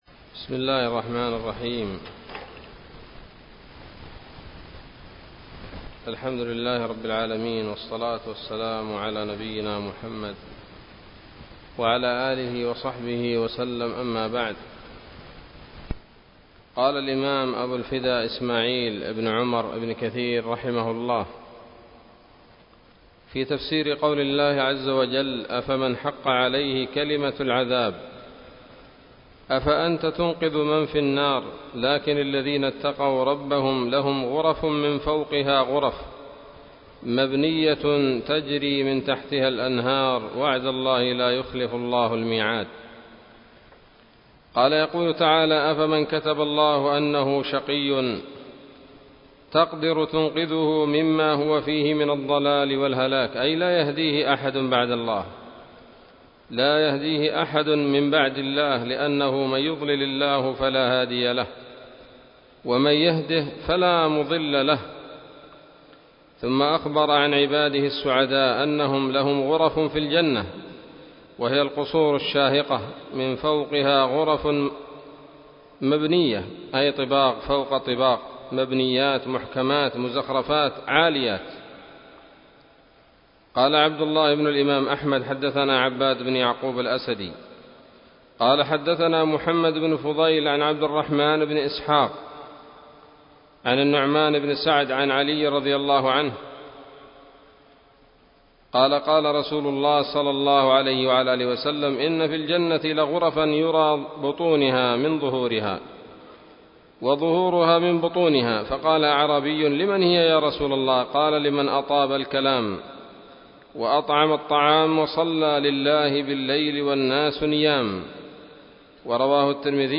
الدرس الخامس من سورة الزمر من تفسير ابن كثير رحمه الله تعالى